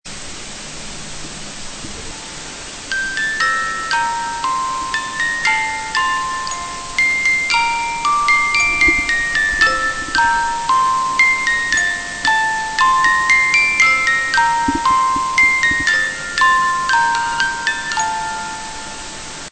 Dans l'exemple qui suit, le souffle est bien apparent dans les 3 premières secondes du fichier.